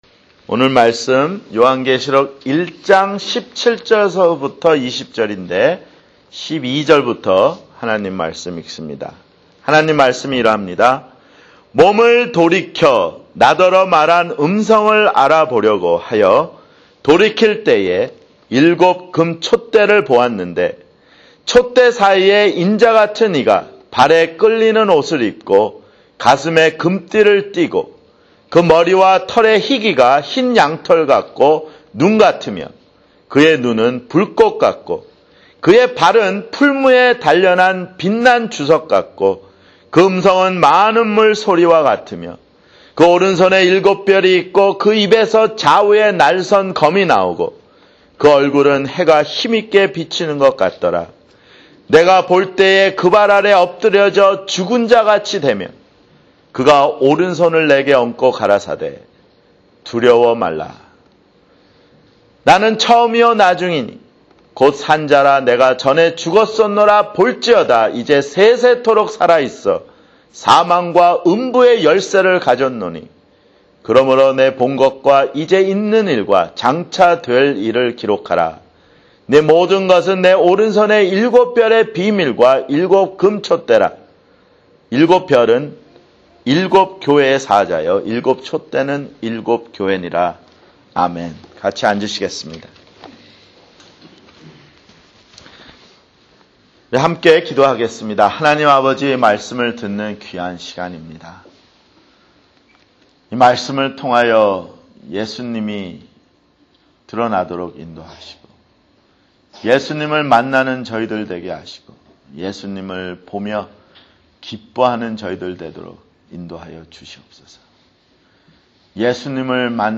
[주일설교] 요한계시록 (11)